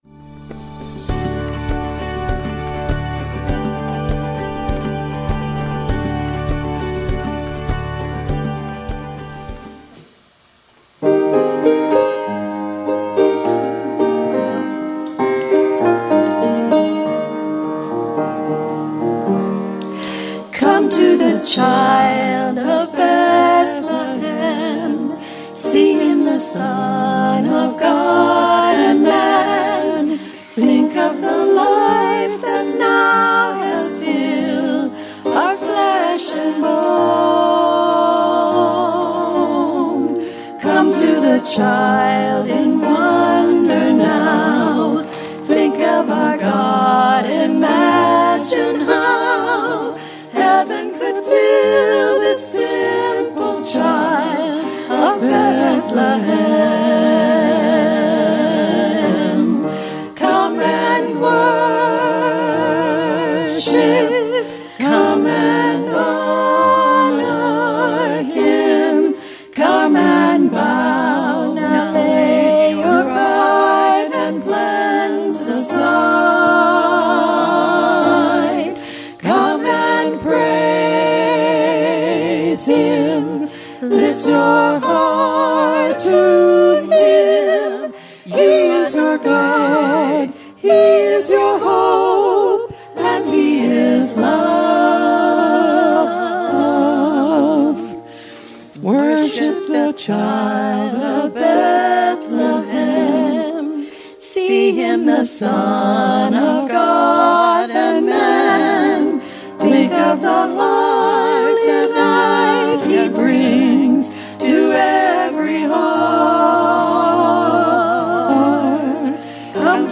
“The Labor of Love” Worship Service – December 15th, 2019 48 minutes 12 seconds
Special Music
Sermon Focus Points